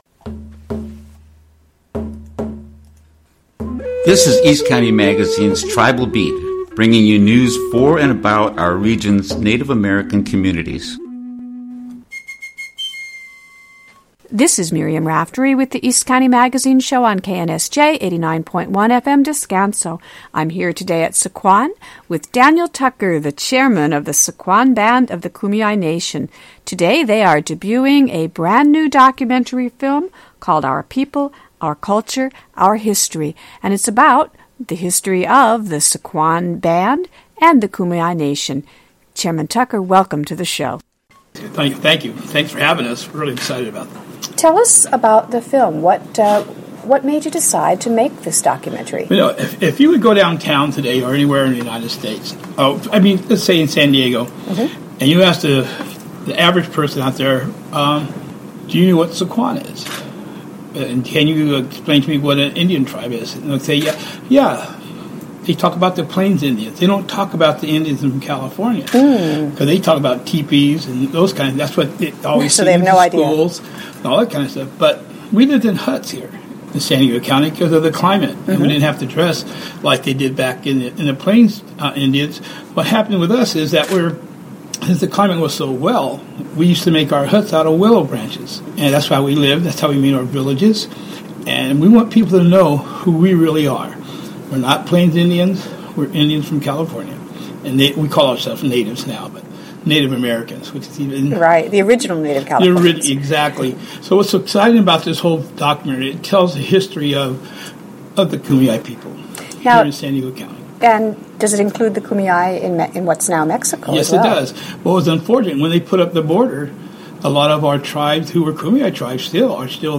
To hear our exclusive interview with Sycuan Chairman Daniel Tucker, click here.